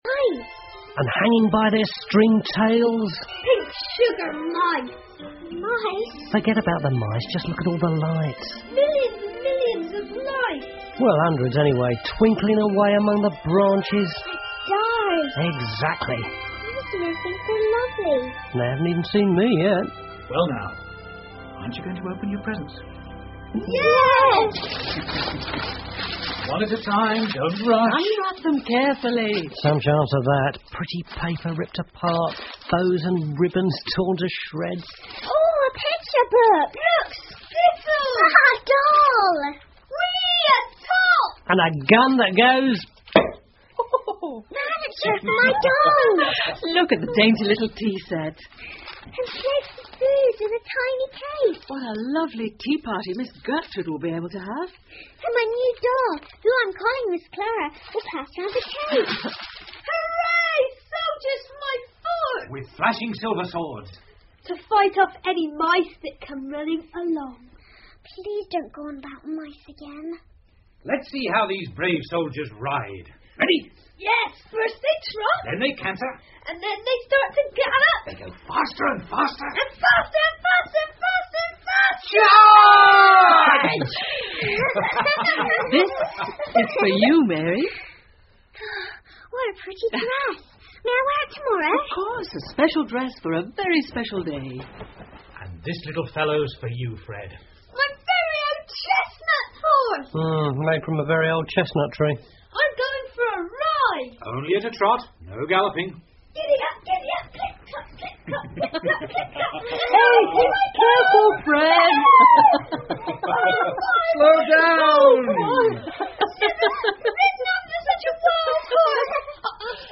胡桃夹子和老鼠国王 The Nutcracker and the Mouse King 儿童广播剧 3 听力文件下载—在线英语听力室